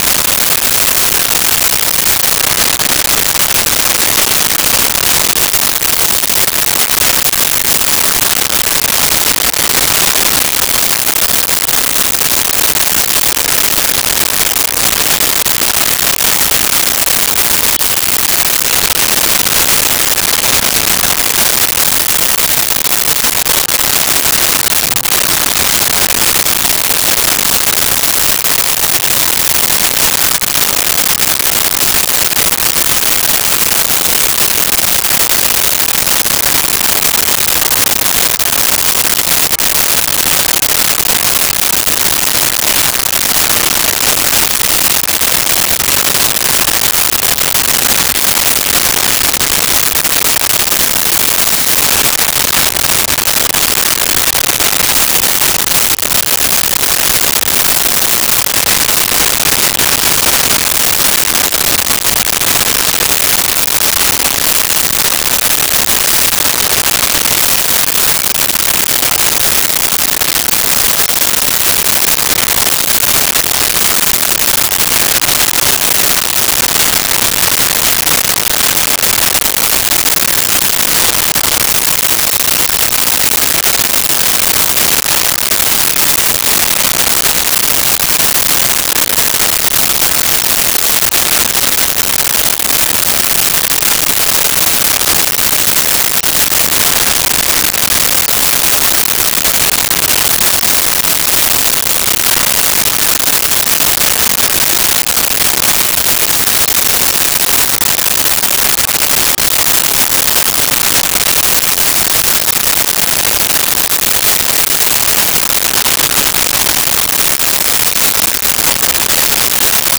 Forest Birds Insect Buzz 02
Forest Birds Insect Buzz 02.wav